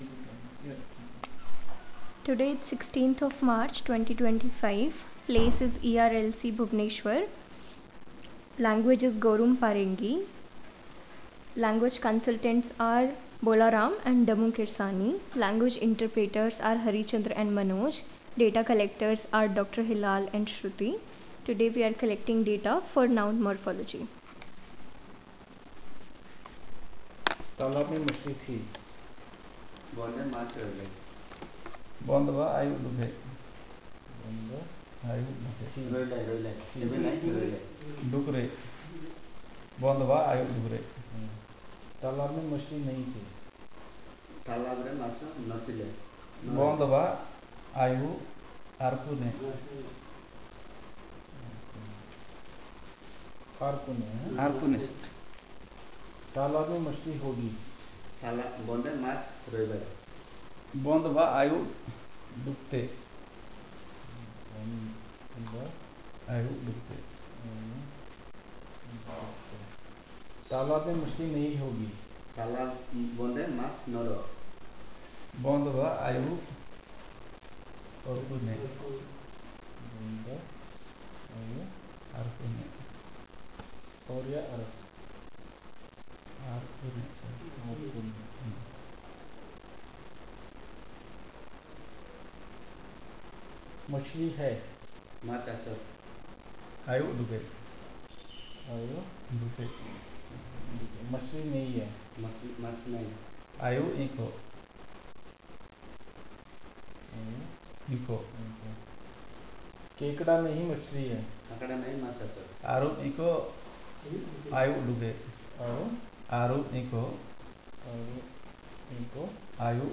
Elicitation of noun morphology - II